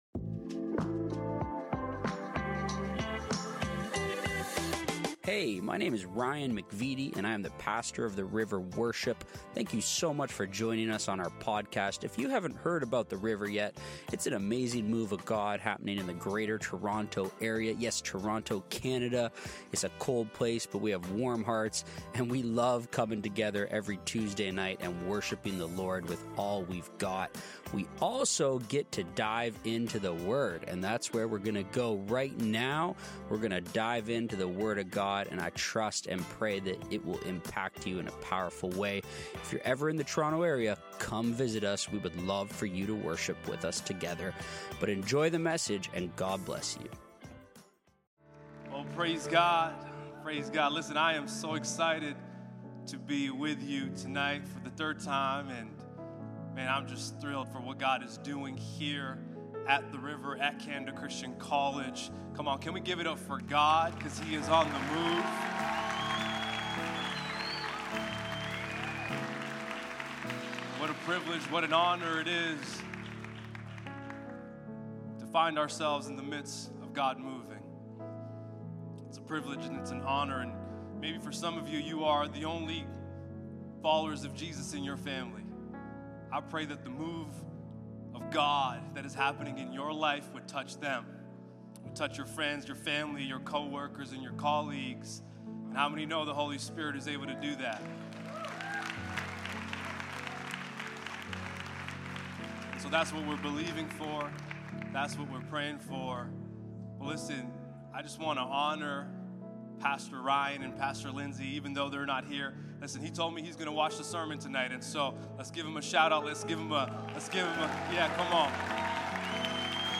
Come Before Winter – The River Worship - Sermons – Podcast